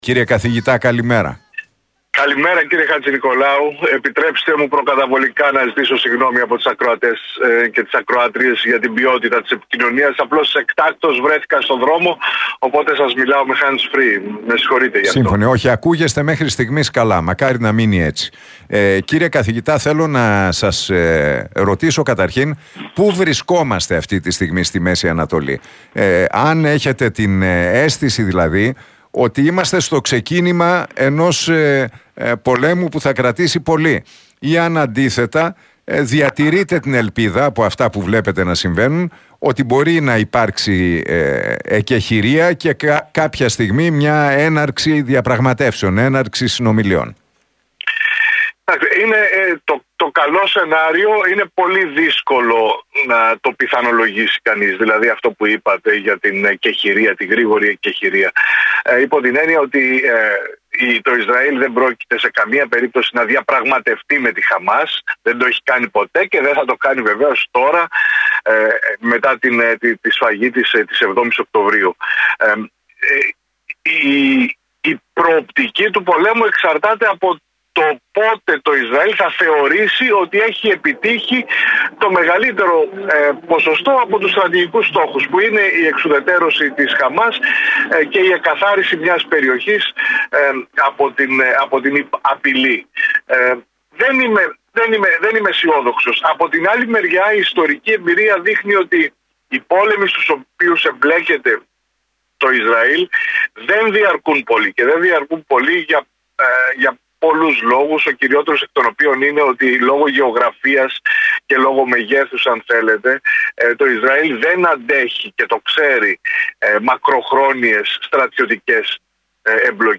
δήλωσε στον Realfm 97,8 και στην εκπομπή του Νίκου Χατζηνικολάου πως «η προοπτική του πολέμου εξαρτάται από το πότε το Ισραήλ θα θεωρήσει ότι έχει επιτύχει το μεγαλύτερο ποσοστό από τους στρατηγικούς στόχους